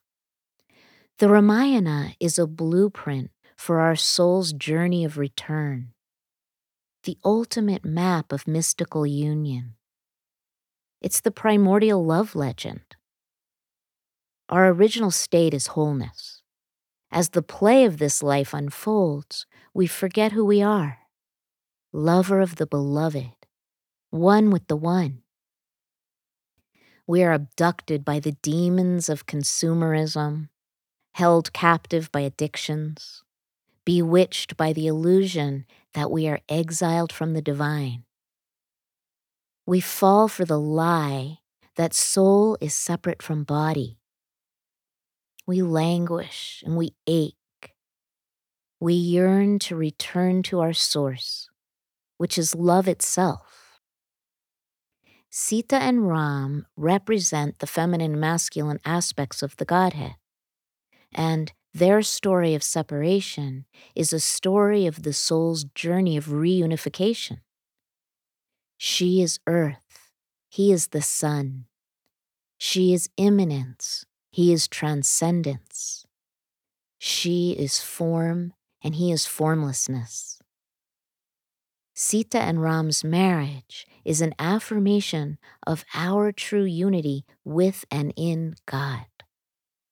This hybrid audio production–part audiobook, part guided course–is a symphony orchestrated to open the doorways of your own heart, while serving to illuminate and integrate the multi-faceted nature of the feminine within us all.
Mirabai Starr–Commentary (excerpt)